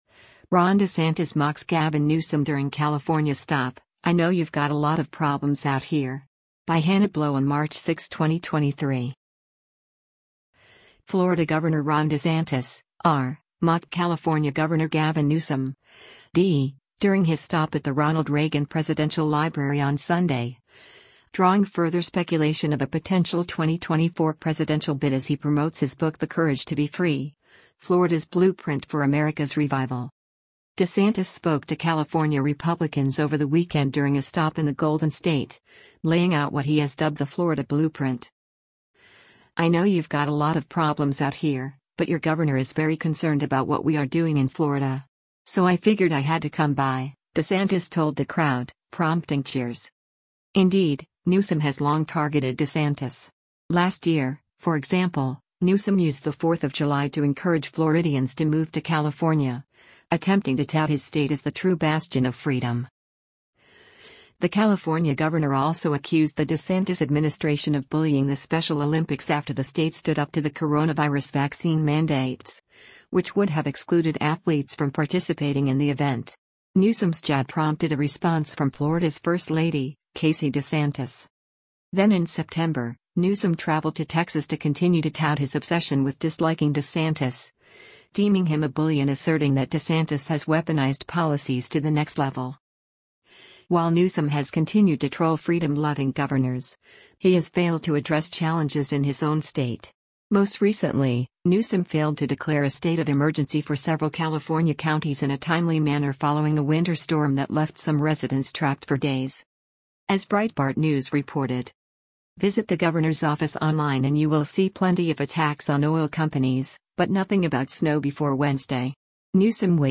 Florida Gov. Ron DeSantis (R) mocked California Gov. Gavin Newsom (D) during his stop at the Ronald Reagan Presidential Library on Sunday, drawing further speculation of a potential 2024 presidential bid as he promotes his book The Courage to Be Free: Florida’s Blueprint for America’s Revival.
DeSantis spoke to California Republicans over the weekend during a stop in the Golden State, laying out what he has dubbed the “Florida Blueprint.”
“I know you’ve got a lot of problems out here, but your governor is very concerned about what we are doing in Florida. So I figured I had to come by,” DeSantis told the crowd, prompting cheers: